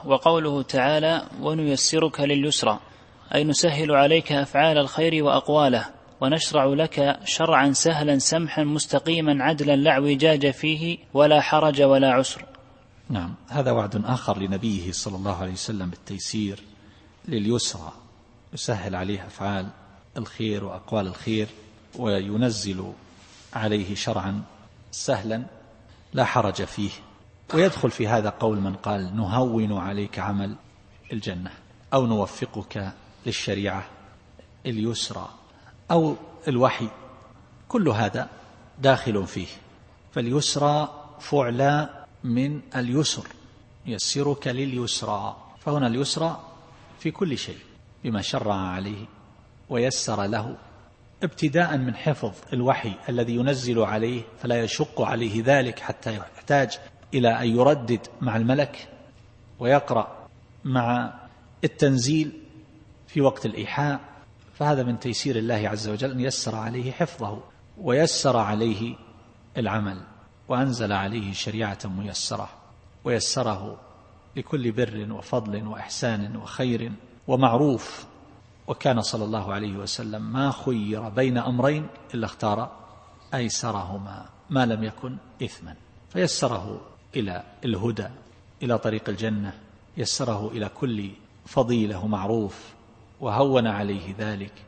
التفسير الصوتي [الأعلى / 8]